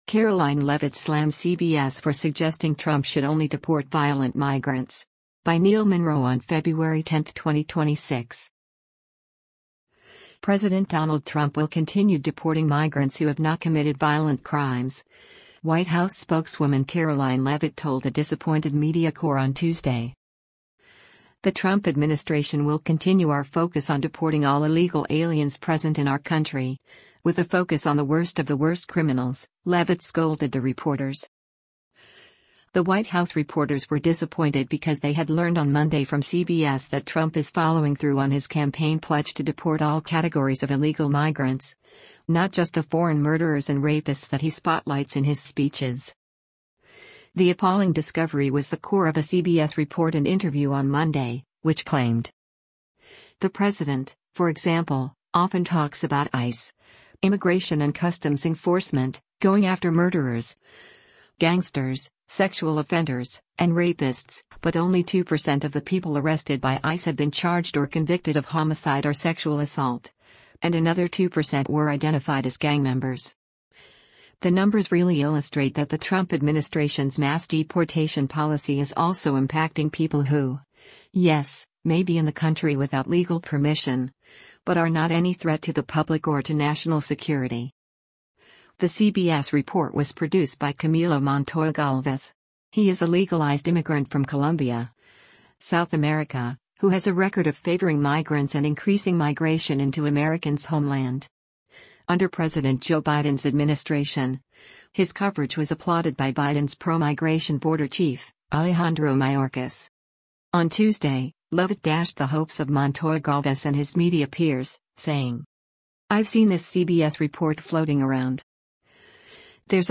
White House Press Secretary Karoline Leavitt speaks during a press briefing in the Brady P